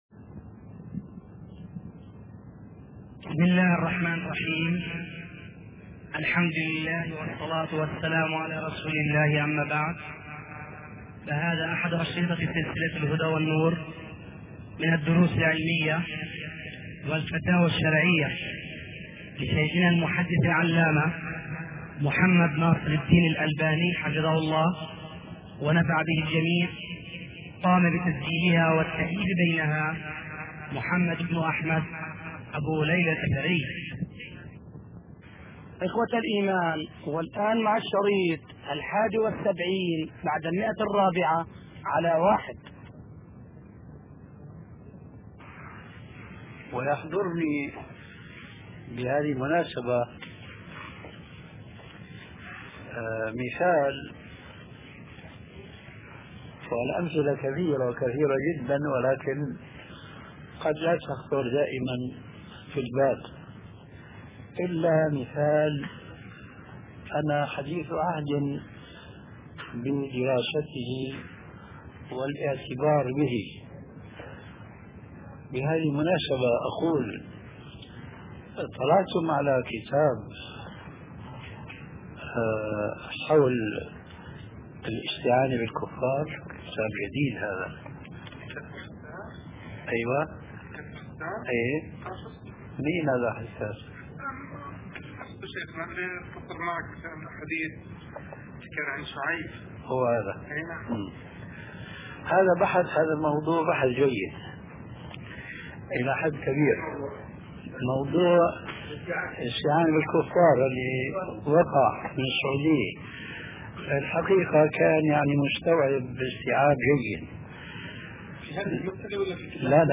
شبكة المعرفة الإسلامية | الدروس | الصلاة على النبي والإمام يخطب |محمد ناصر الدين الالباني